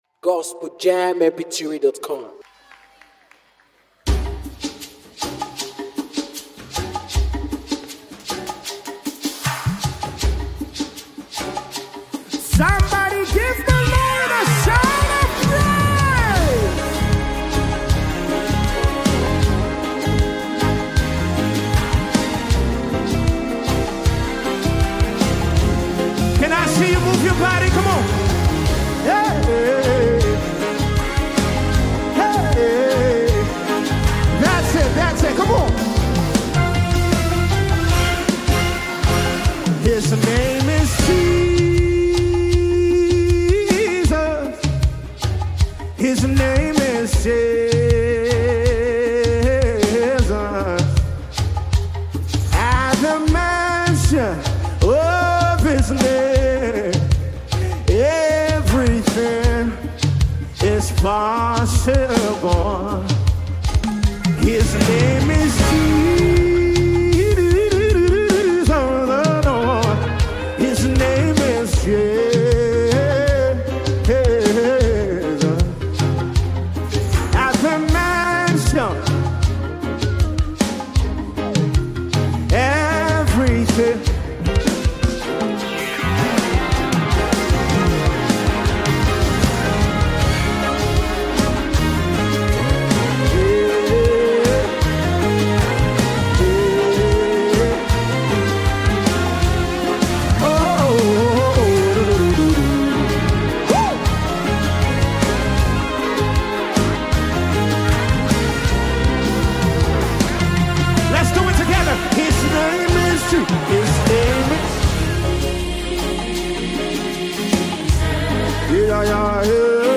heartfelt gospel song
With sincere lyrics and a worshipful sound